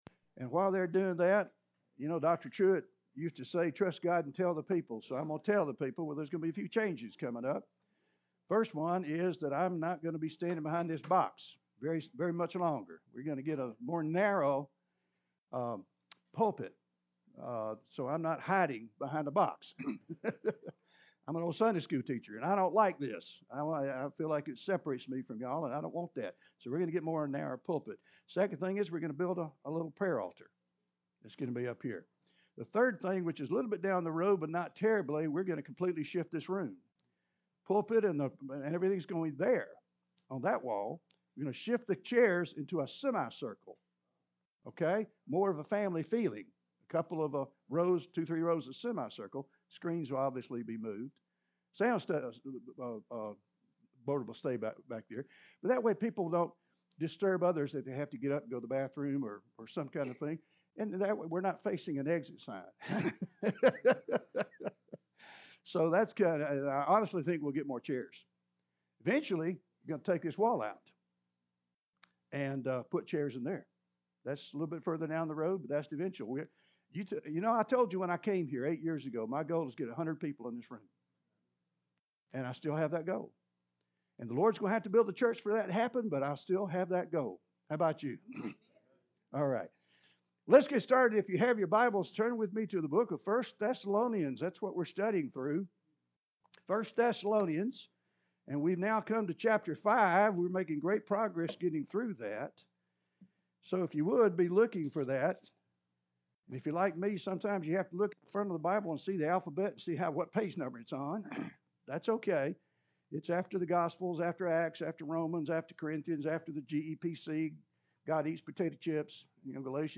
(Sermon Series)